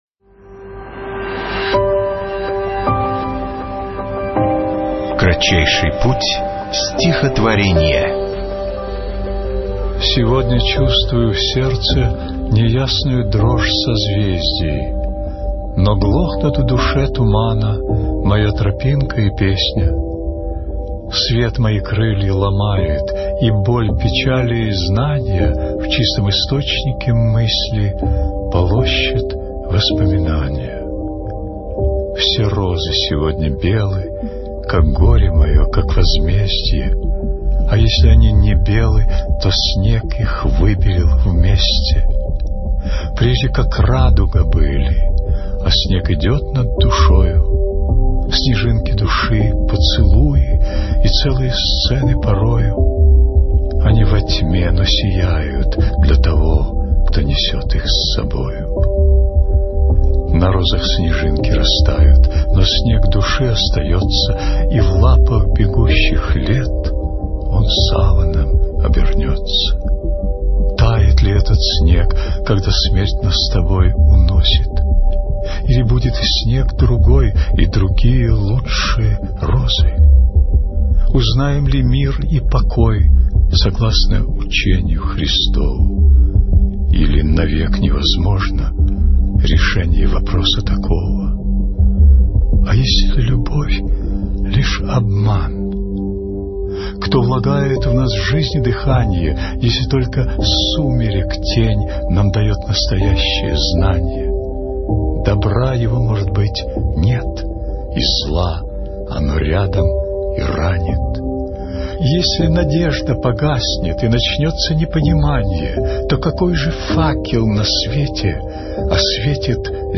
1. «Федерико Гарсиа Лорка – Осенная песня (читает Николай Мартон)» /
Garsia-Lorka-Osennaya-pesnya-chitaet-Nikolay-Marton-stih-club-ru.mp3